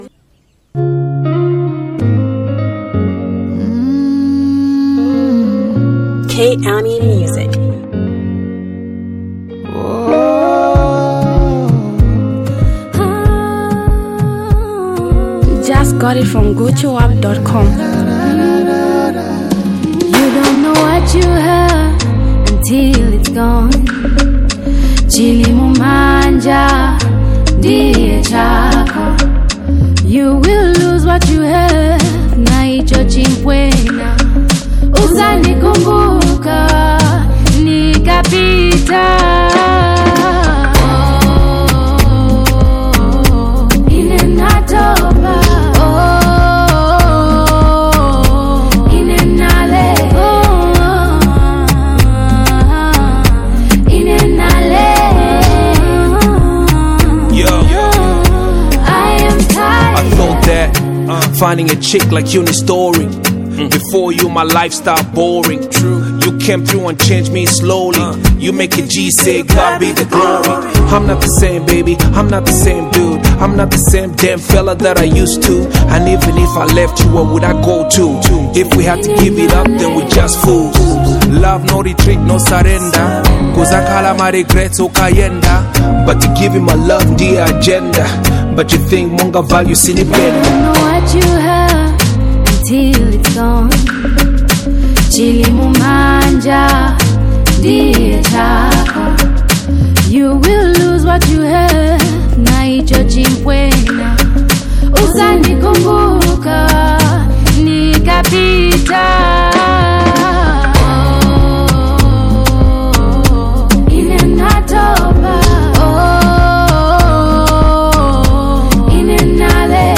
rap
melodic sound